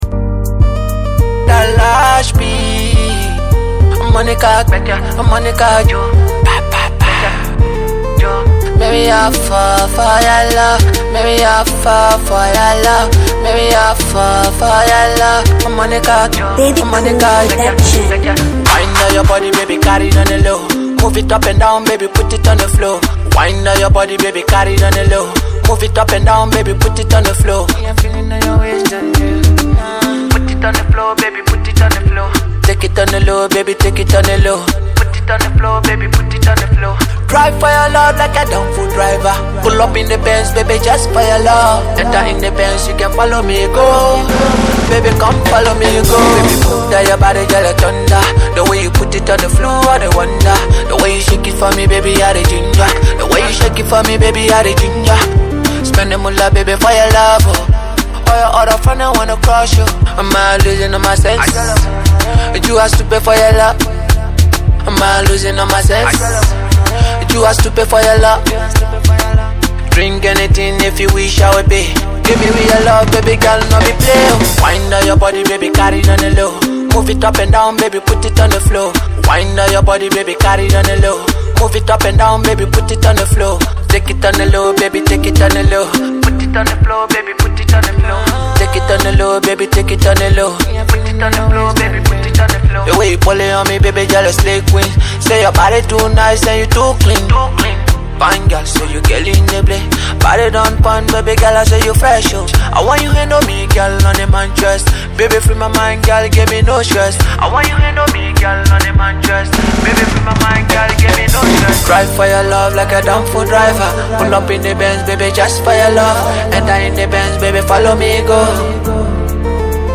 / Afrobeats, Afro-Pop / By